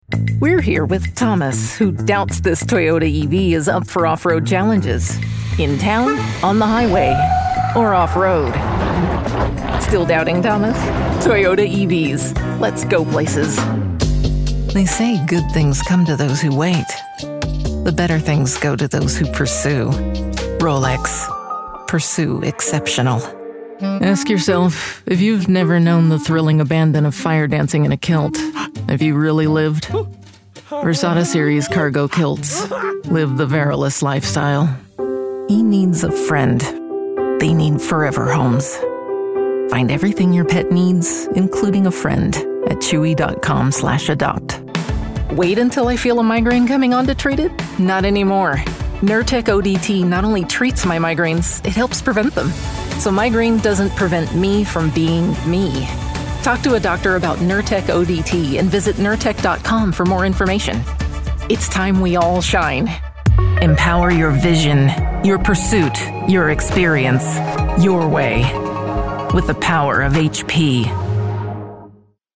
Confident, approachable, sincerity that builds connection.
Commercial, conversational, luxury, wry, warm, authentic, anthemic
General American, American West Coast
Middle Aged